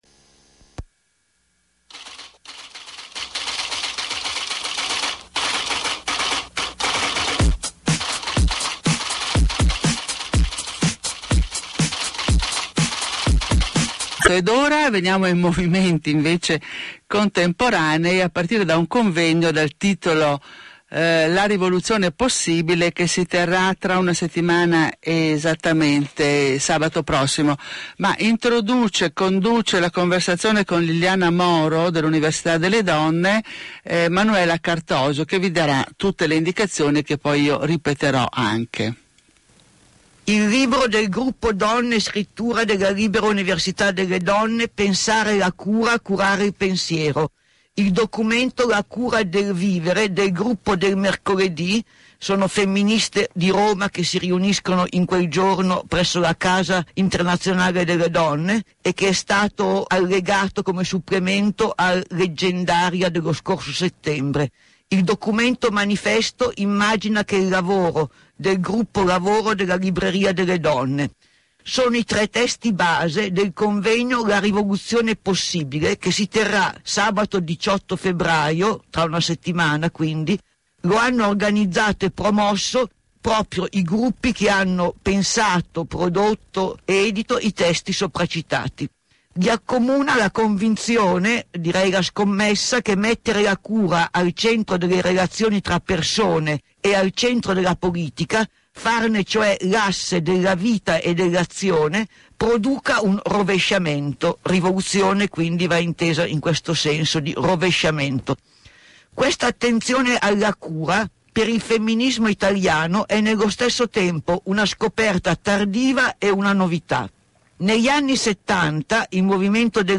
sabatolibri-intervista.mp3